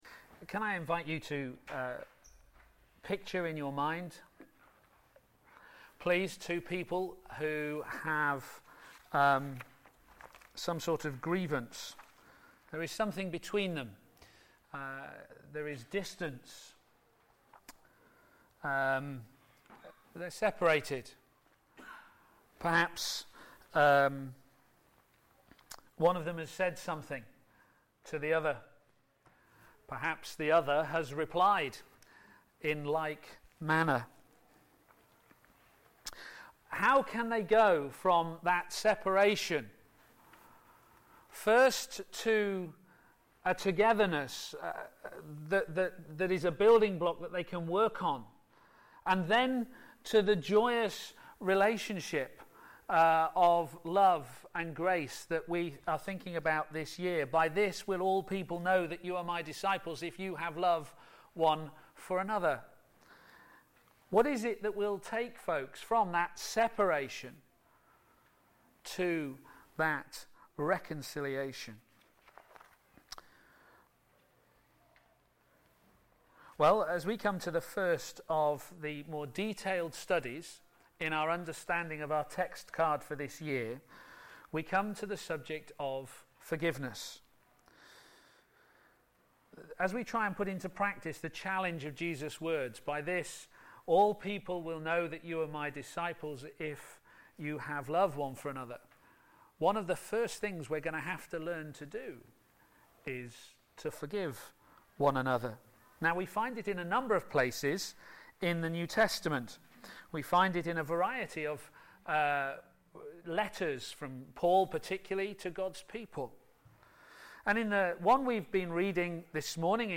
Media Library Media for a.m. Service on Sun 12th Jan 2014 10:30 Speaker
Colossians 3:1-17 Series: Love is all you need? Theme: Forgive one another Sermon In the search box below, you can search for recordings of past sermons.